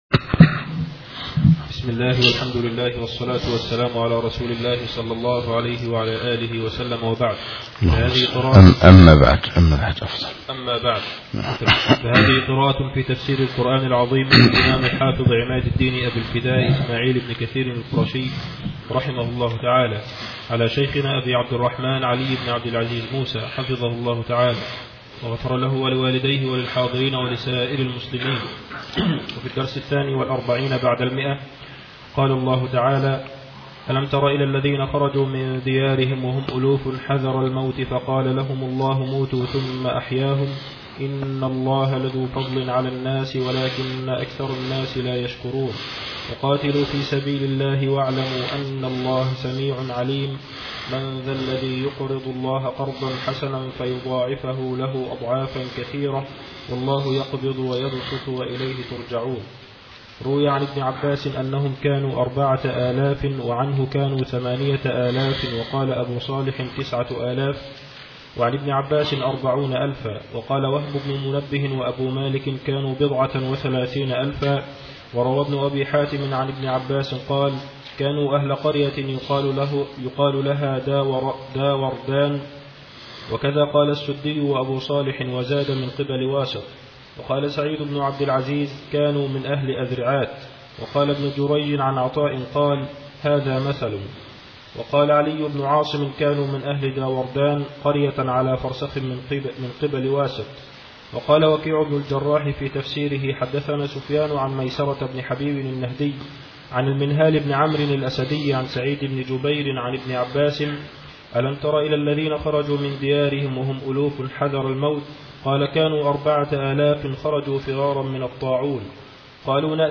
الدرس الثاني والاربعون بعد المائة الأولي